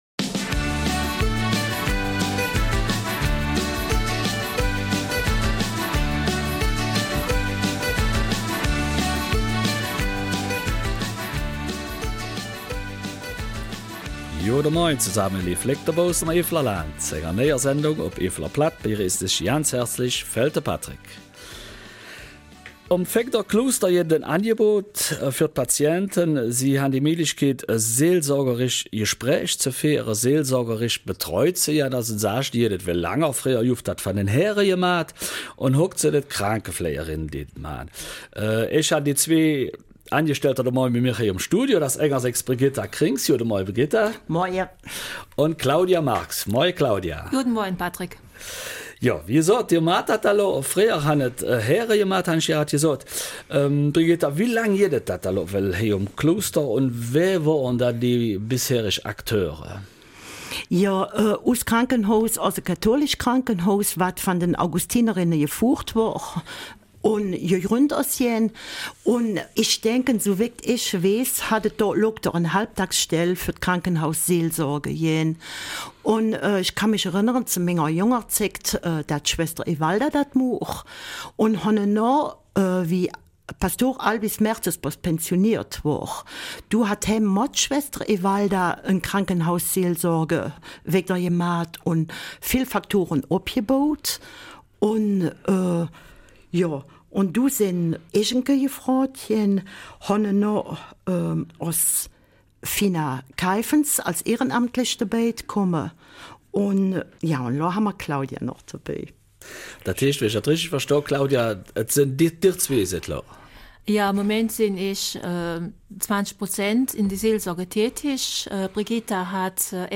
Eifeler Mundart: Seelsorge im Krankenhaus
Beide sprechen in der Eifeler Mundartsendung über ihre Erfahrungen und die Herausforderungen ihrer Tätigkeit.